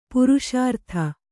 ♪ puruṣārtha